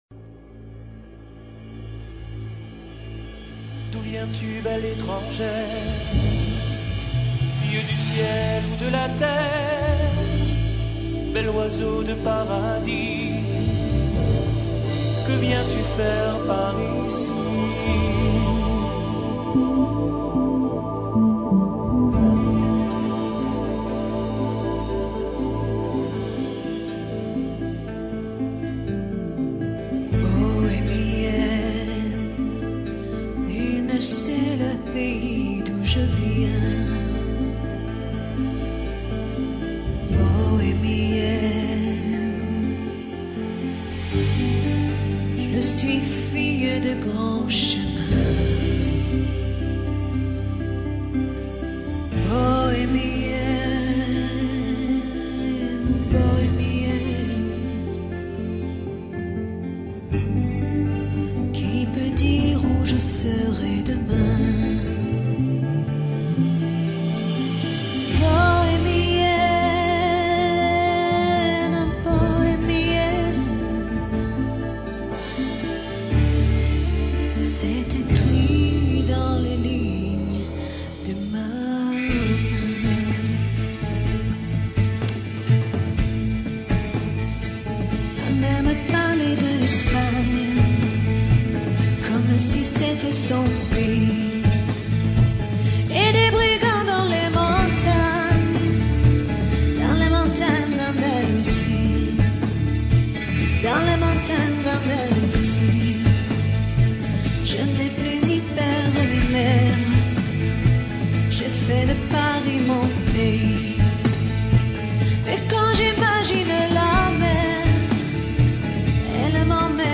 Она - скорее к лиричному слайд-шоу....